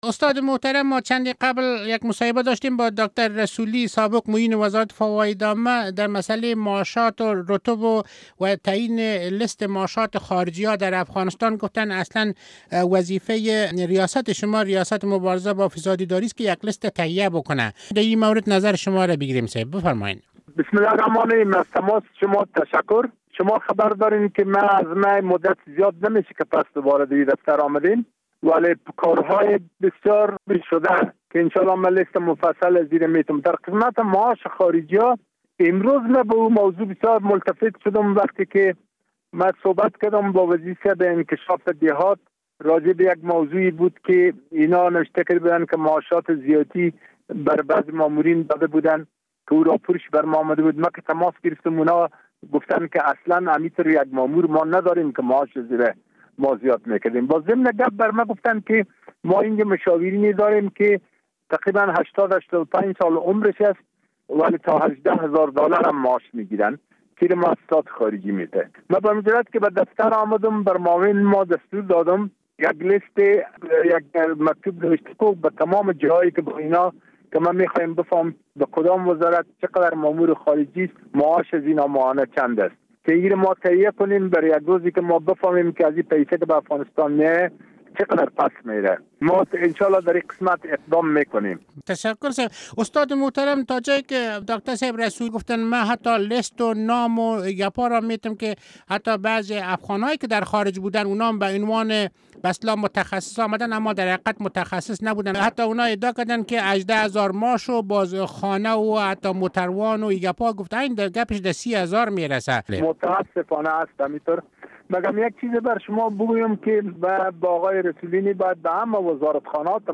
مصاحبه با عزیزالله لودین در مورد معاشات بلند متخصصین خارجی